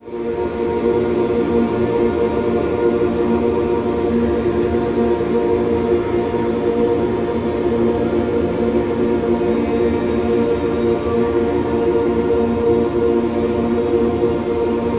Jednym z podstawowych Ćwiczeń Duchowych Eckankar jest nucenie HU, świętego imienia Boga. HU, wymawiane jako hjuu…, otwiera serce na niebiańską miłość Boga.
Posłuchaj grupy ludzi śpiewających HU.